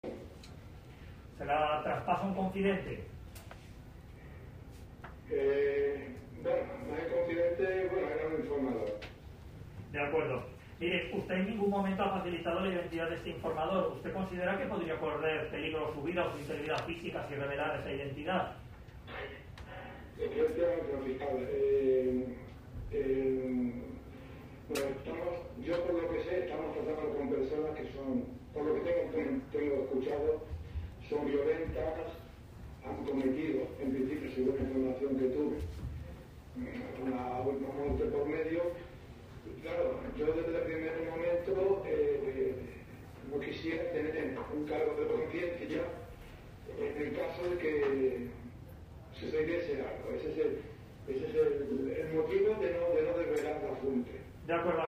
El policia ha declarat per videoconferència i ha descartat, un cop més, posar nom i cognoms al delator: “Pel que jo sé són persones violentes, que havien comès uns fets amb una mort pel mig i no voldria tenir cap càrrec de consciència si se sabés qui és”-